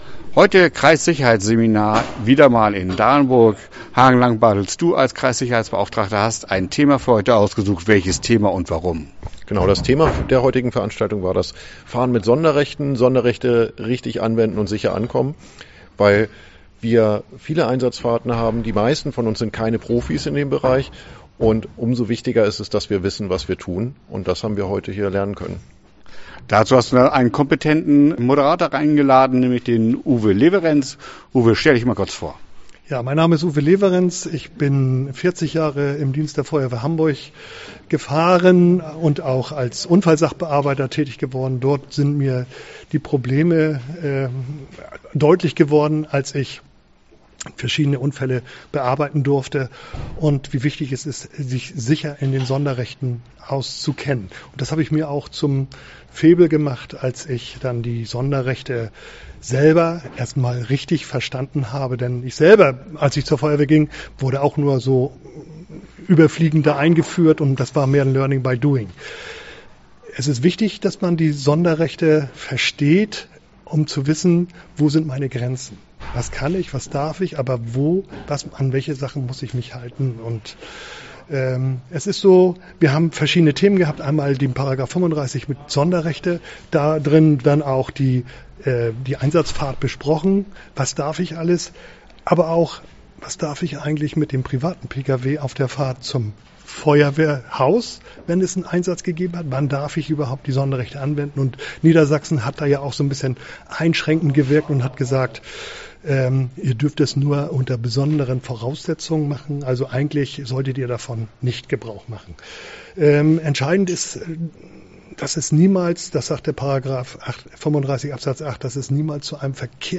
Interview Kreissicherheitsseminar